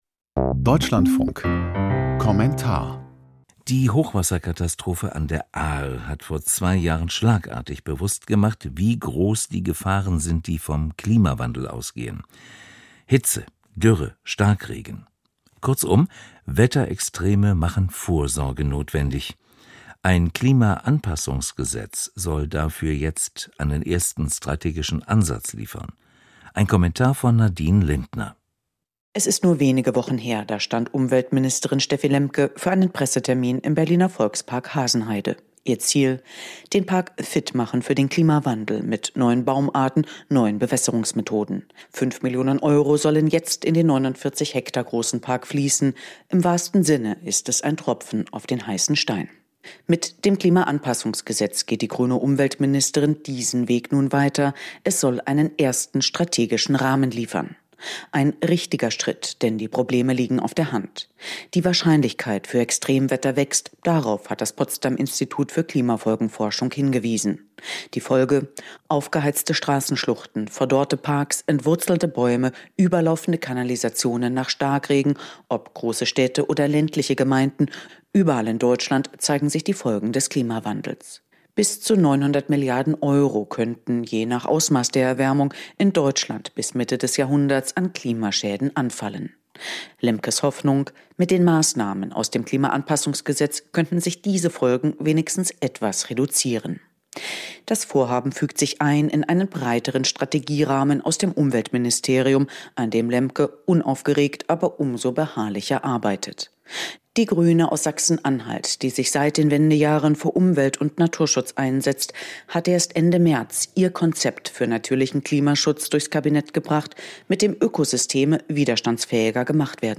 Kommentar zum Klimaanpassungsgesetz der Bundesregierung